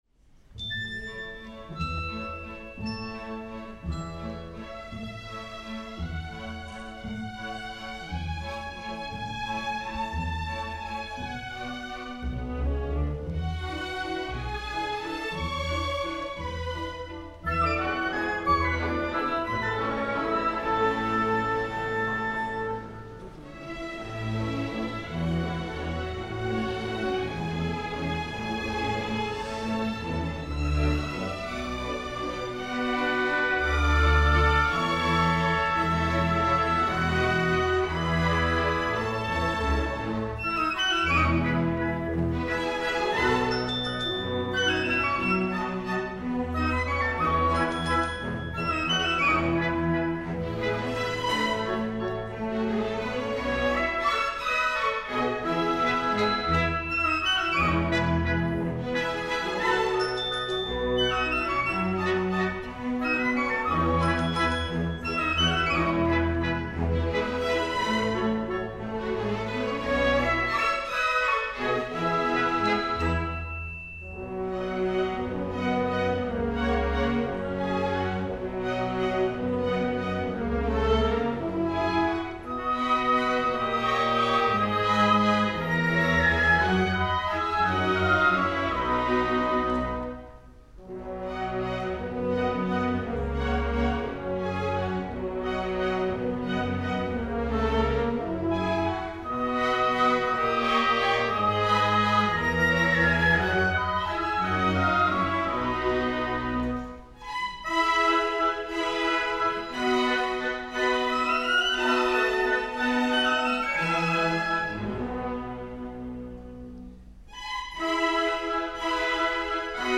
Gran Vals για Ορχήστρα (live)